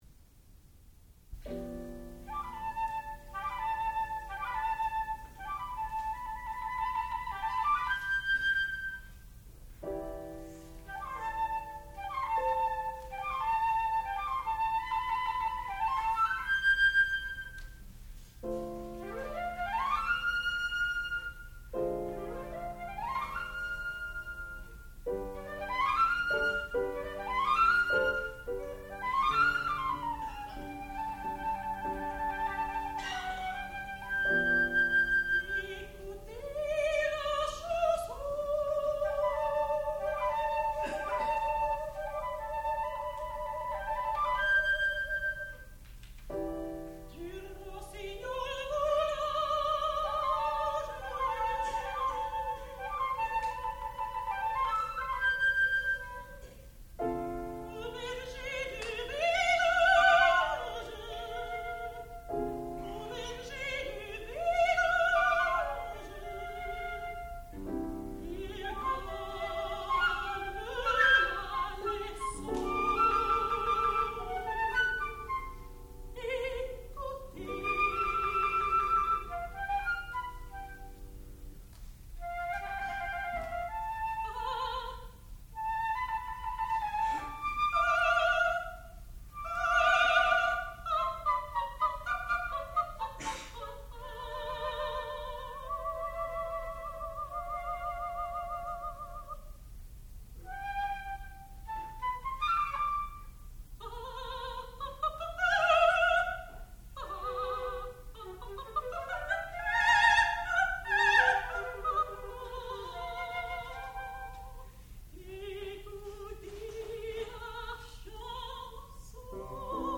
Le Rossignol (flute, voice, piano)
sound recording-musical
classical music
soprano